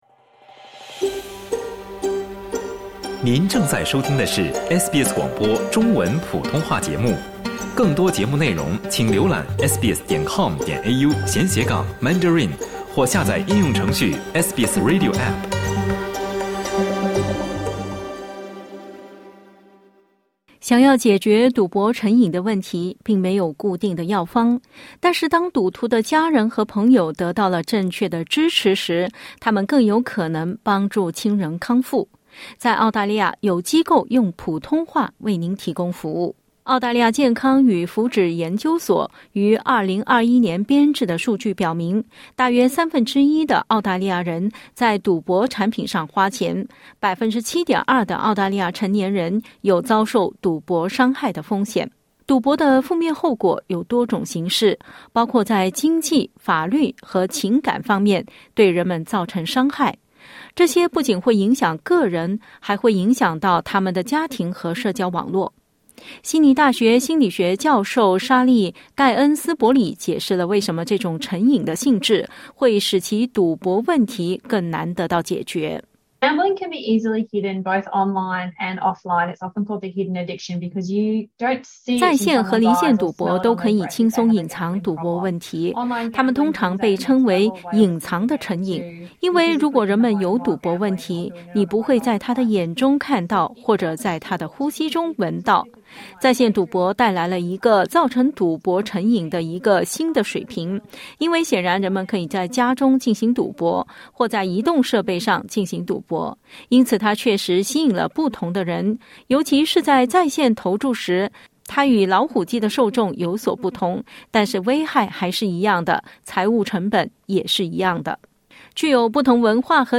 （他的名字和声音已被更改以保护他的身份。）